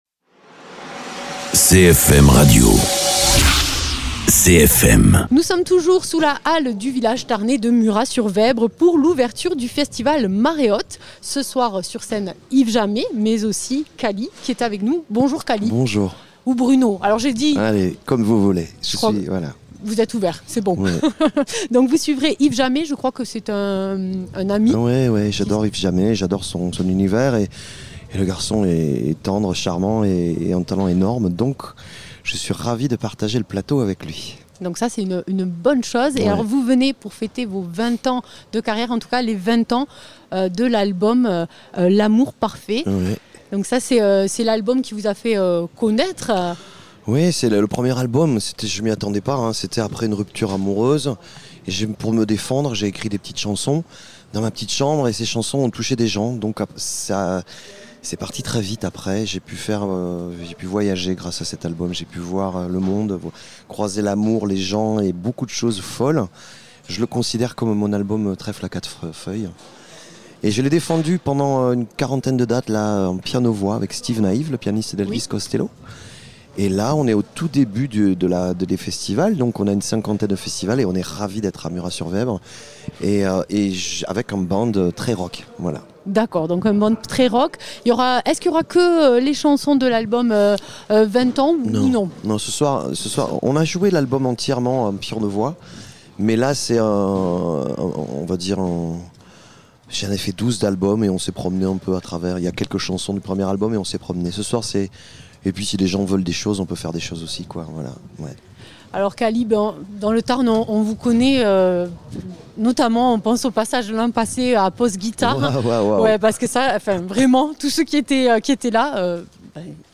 Du 17 au 20 mai s’est déroulé le Festival Marée Haute #2 sur les Monts de Lacaune (Tarn) et la Montagne du Haut Languedoc (Hérault). L’équipe de CFM a réalisé une émission spéciale pour l’ouverture.
Interviews